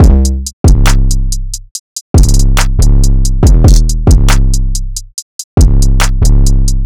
drumloop 4 (140 bpm).wav